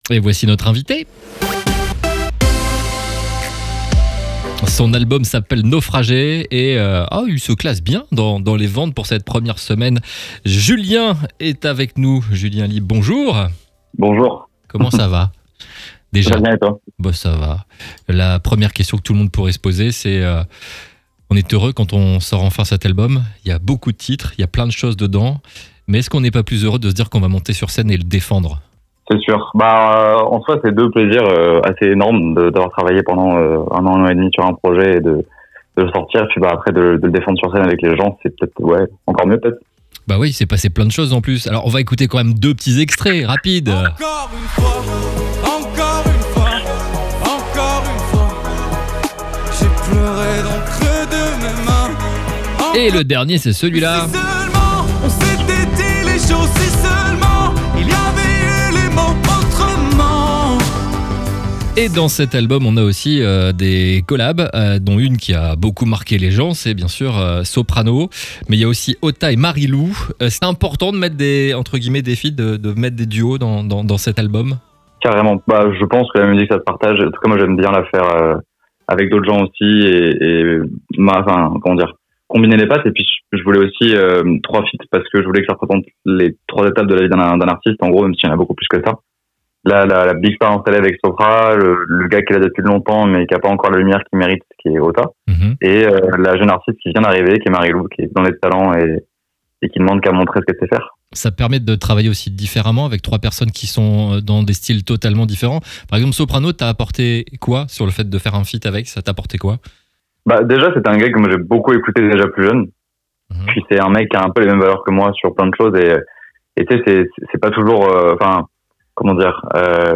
Les interviews exclusifs de RCB Radio